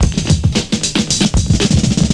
112FILLS02.wav